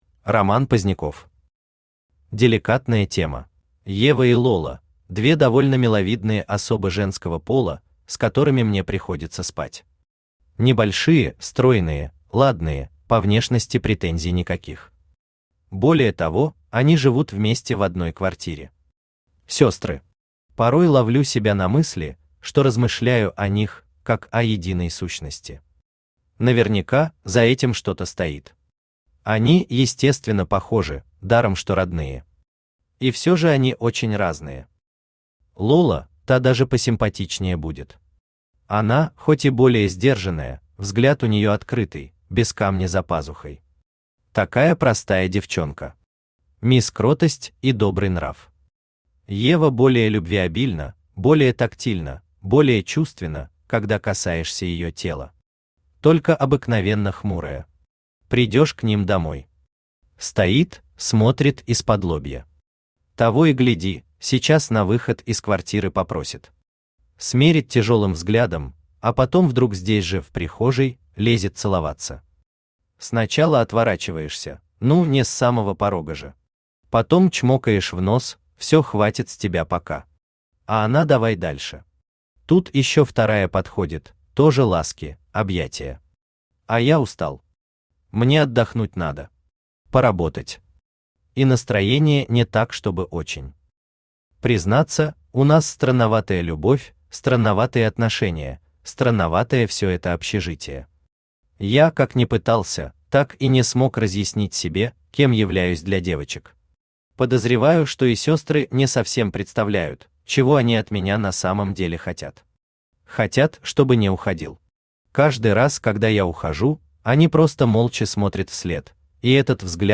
Аудиокнига Деликатная тема | Библиотека аудиокниг
Aудиокнига Деликатная тема Автор Роман Поздняков Читает аудиокнигу Авточтец ЛитРес.